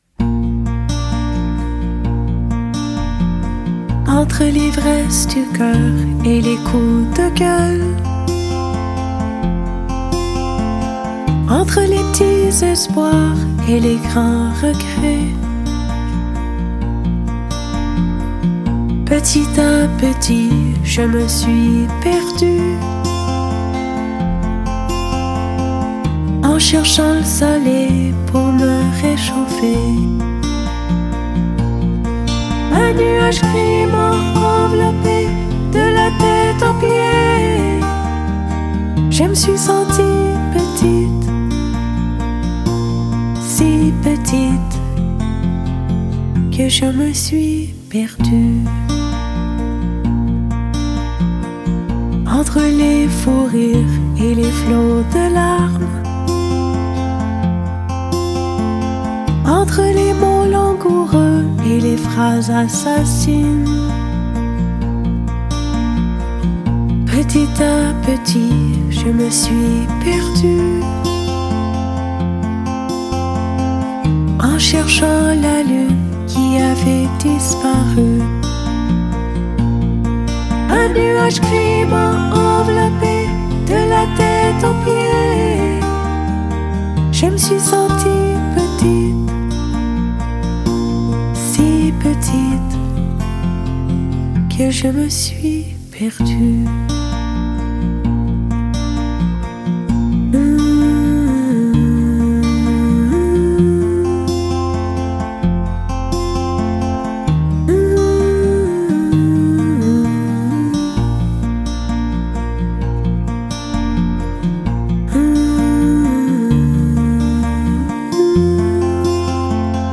" Dépression " est un livre audio rempli de petits conseils. Oui, des conseils livrés avec douceur et émotion.